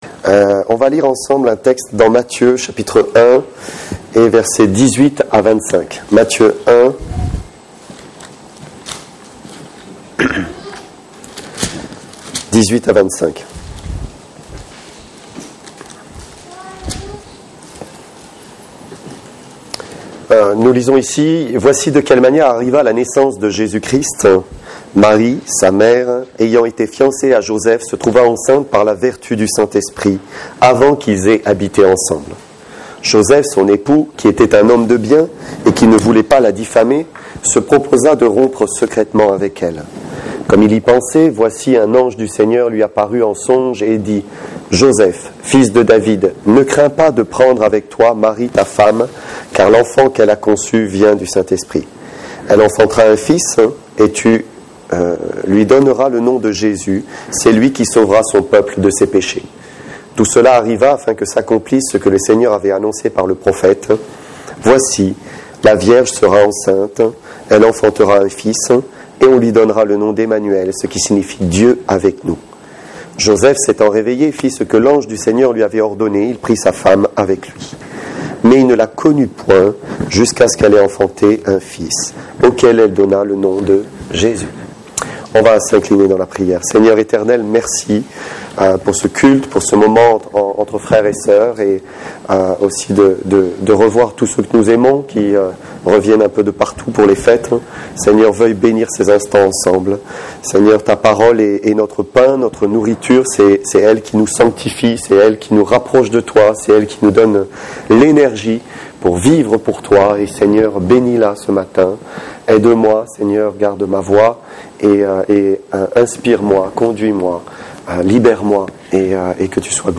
Base de la prédication : Évangile de Mathieu chapitre 1 verset 18 à 25 .